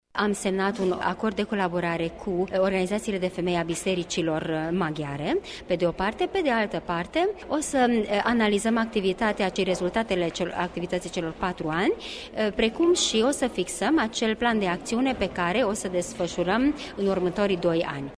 Aceasta a început la ora 10,00 în Sala mică a Palatului Culturii din municipiu în prezența mai multor personalități.